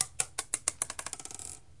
瓦砾碎石滚落
描述：用90°XY指向的Zoom H2记录。
标签： 雪崩 瓦砾 毁坏 滑动 滚动 山崩
声道立体声